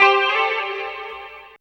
137 GTR 7 -L.wav